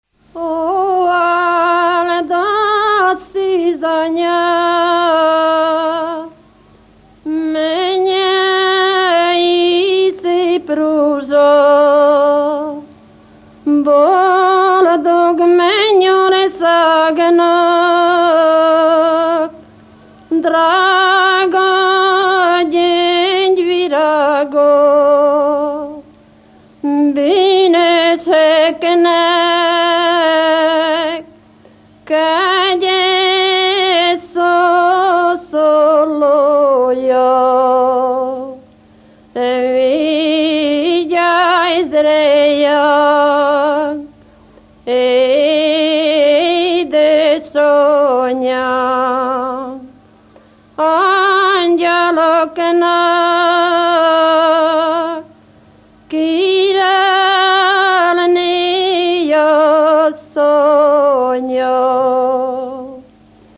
Moldva és Bukovina - Baranya vm. - Lészped
ének
Stílus: 4. Sirató stílusú dallamok